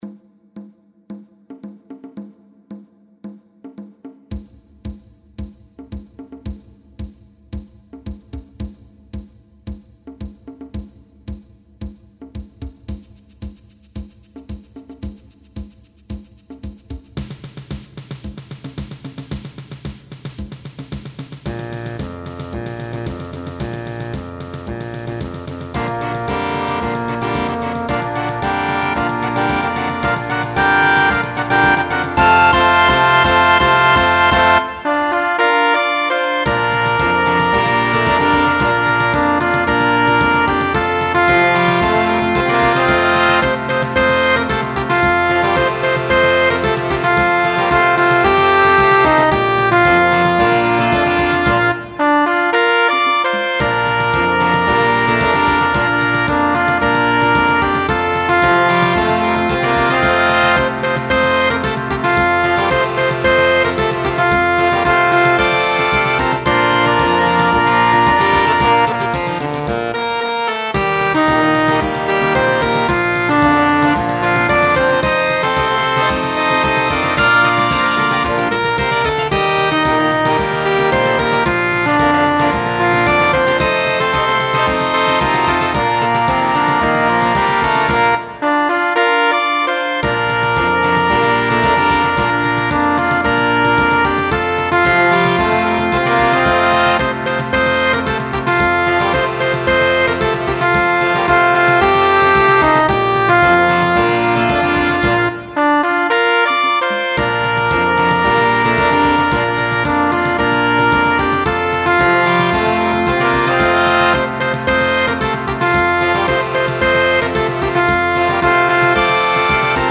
Para banda sinfónica